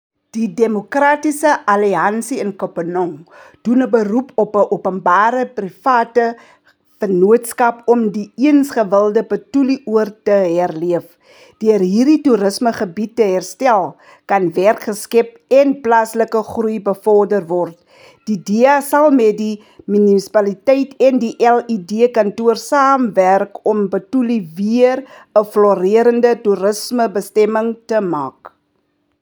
Issued by Cllr. Hessie Shebe – DA Councillor Kopanong Local Municipality
Afrikaans soundbites by Cllr Hessie Shebe and